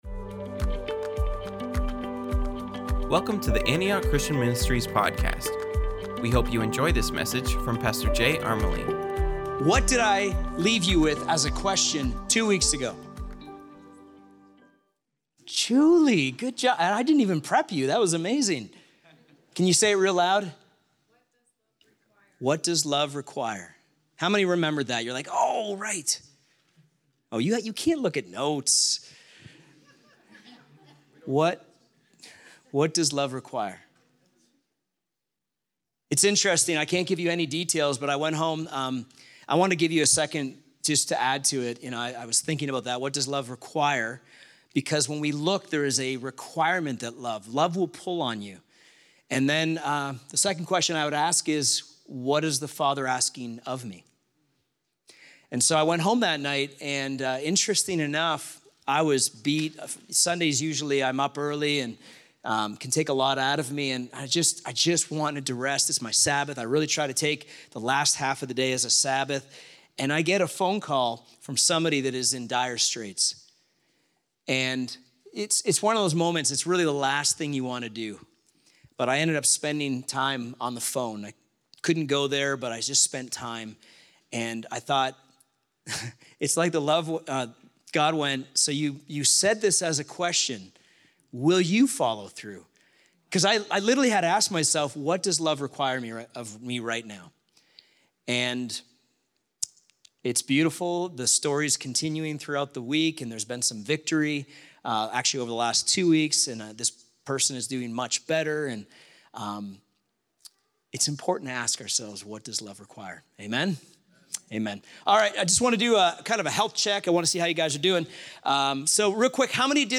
Sermons | Ascent Church